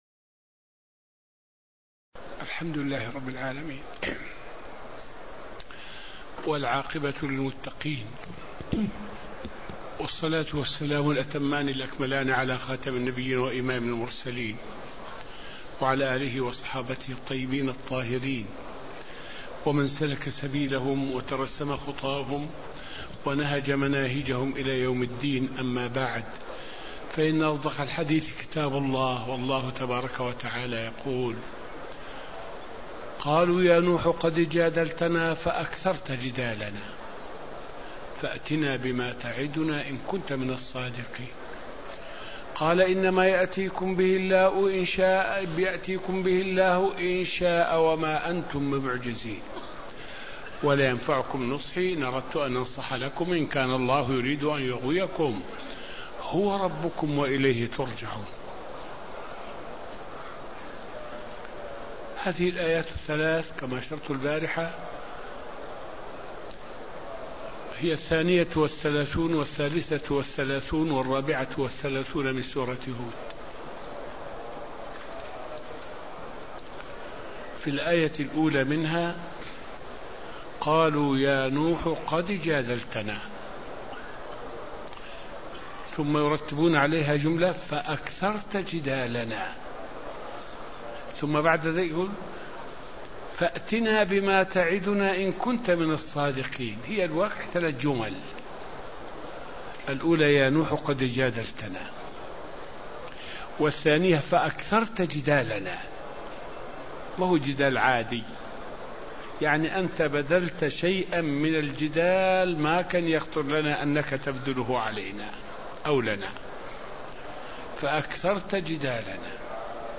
الدرس التاسع والخمسون من دروس تفسير سورة هود والتي ألقاها الشيخ في رحاب المسجد النبوي حول الآيات من الآية 31 إلى الآية 32.